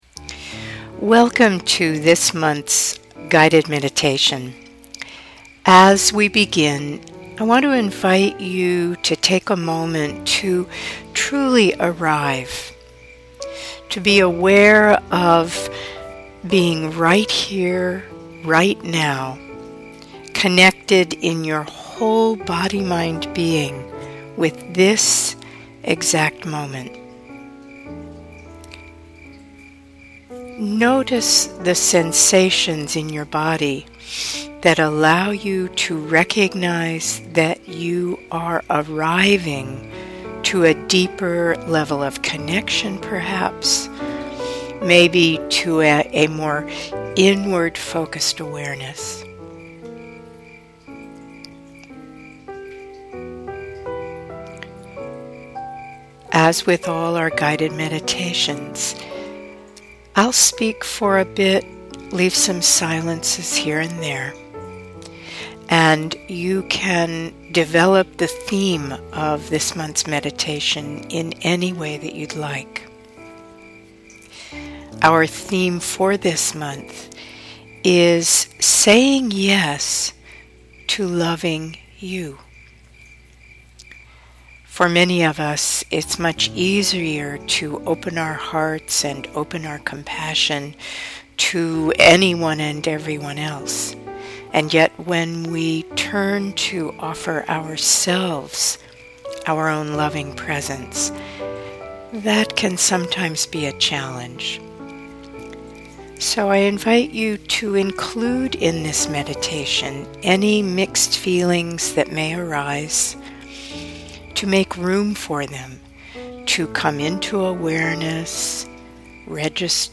2023 November Meditation